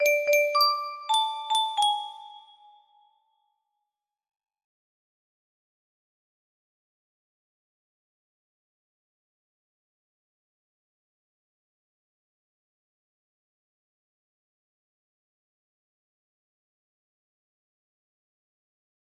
cool music music box melody